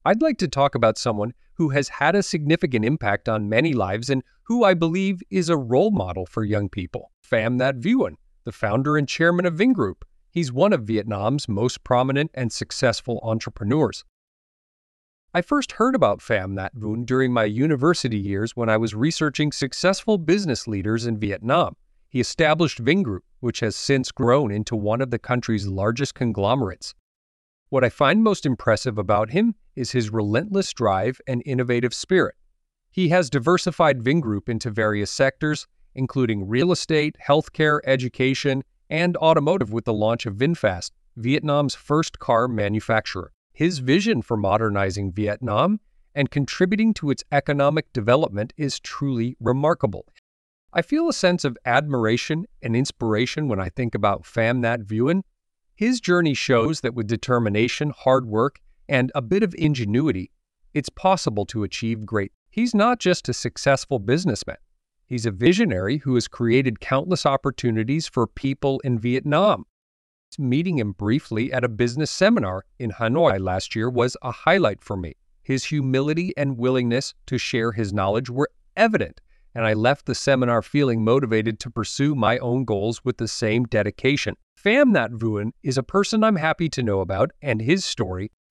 Trong bài viết này, Mc IELTS chia sẻ câu trả lời mẫu band 8.0+ từ cựu giám khảo IELTS, kèm theo các câu hỏi mở rộng kèm theo các câu hỏi mở rộng và bản audio từ giáo viên bản xứ để bạn luyện phát âm, ngữ điệu và tốc độ nói tự nhiên.